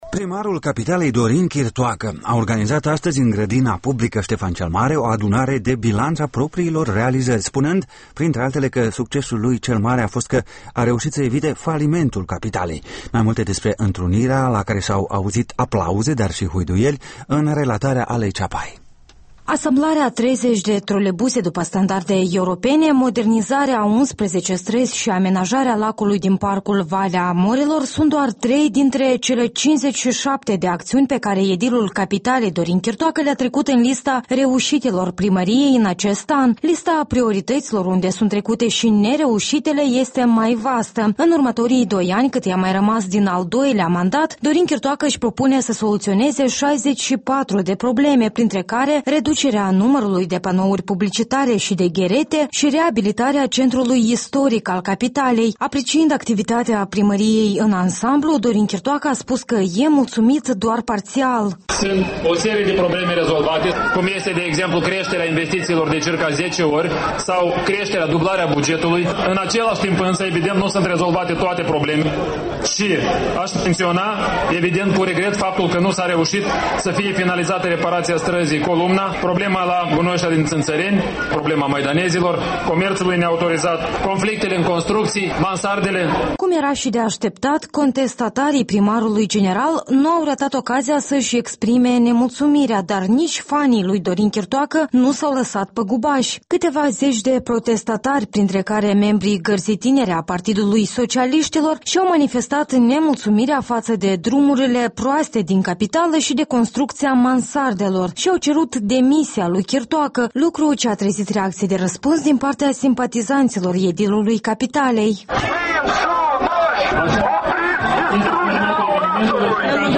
Primarul de Chişinău la ora bilanţului, aplaudat şi huiduit
Într-un bilanț în aer liber, edilul capitalei Dorin Chirtoacă a spus că a făcut multe, dar mai are multe de făcut.